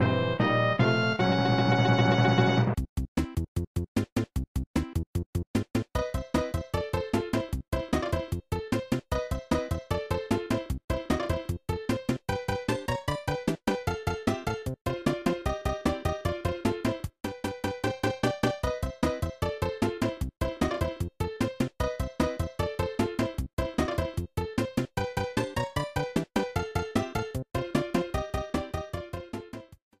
Added fadeout